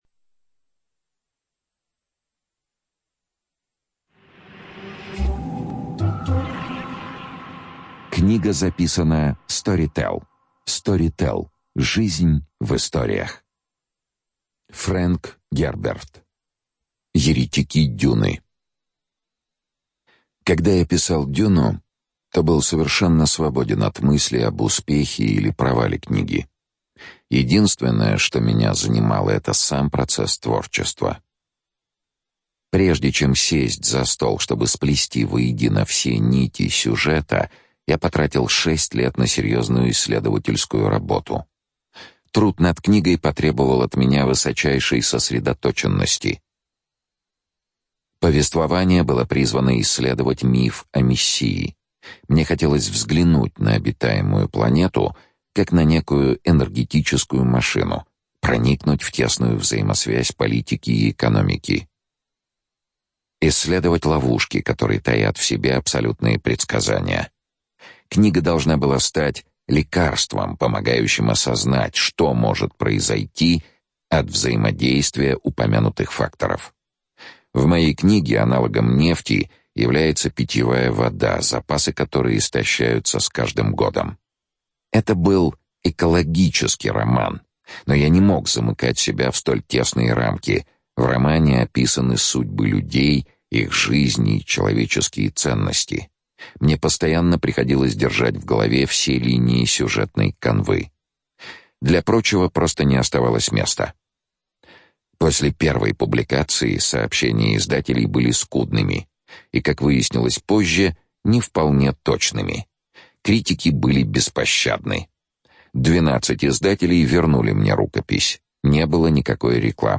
ЧитаетЧонишвили С.
Студия звукозаписиStorytel